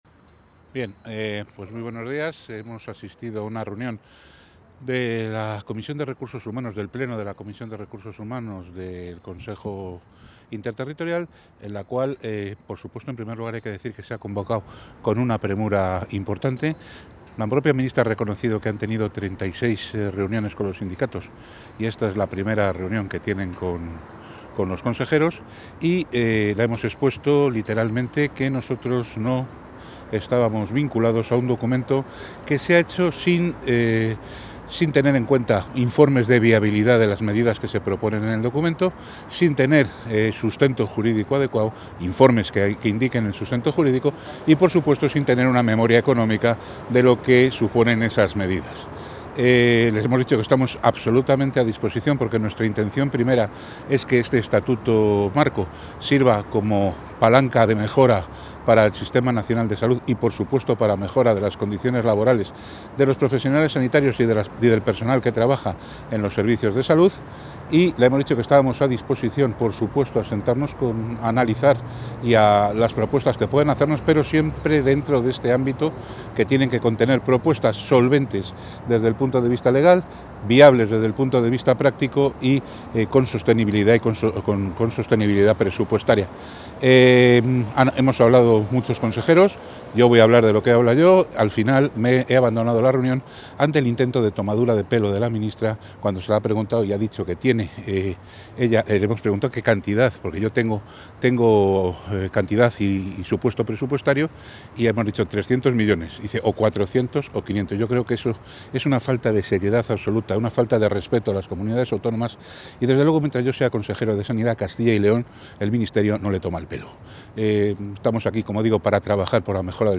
Declaraciones del consejero de Sanidad con motivo del Consejo Interterritorial del Sistema Nacional de Salud | Comunicación | Junta de Castilla y León
Intervención del consejero.